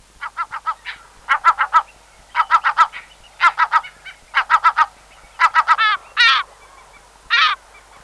Lesser Black-backed Gull
Lesser-Black-backed-Gull.mp3